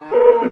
mob / cow / hurt3.ogg
hurt3.ogg